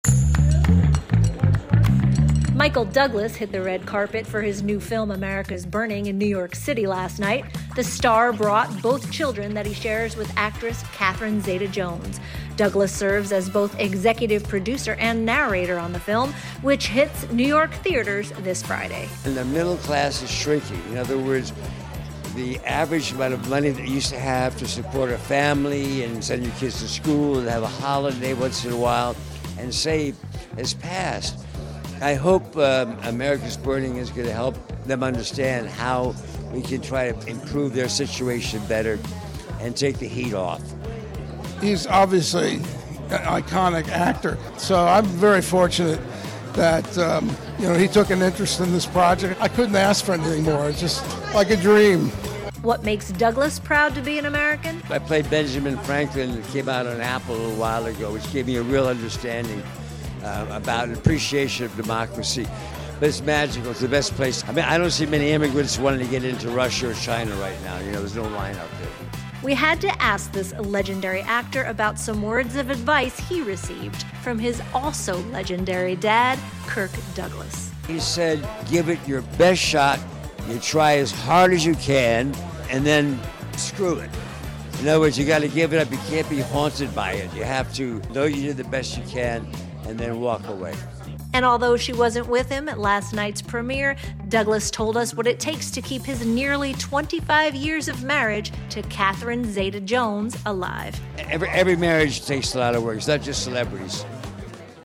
We spoke with Douglas about the film, as well as what makes him still proud to be an American, his best advice from his late father, legendary actor Kirk Douglas and what it takes to keep his nearly 25-year marriage to Catherine Zeta-Jones alive.